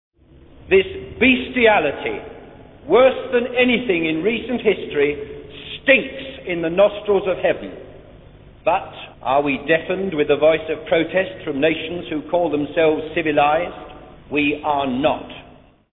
This memorial service was held following the shooting down of the Viscount  Hunyani, VP WAS near Kariba 5 days earlier.